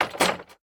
small_destroy1.ogg